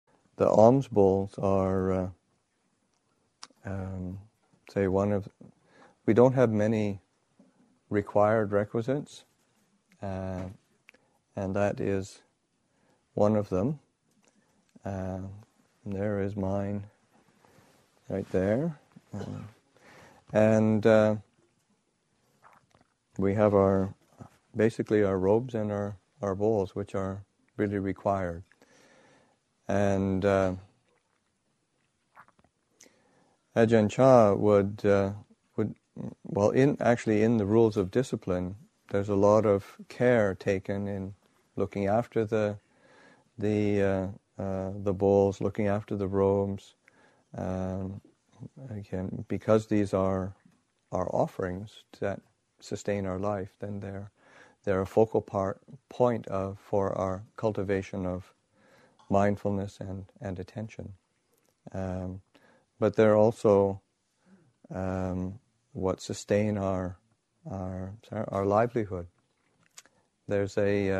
The monks’ requisites sustain our livelihood and are a focal point for our cultivation of mindfulness and attention. Reflection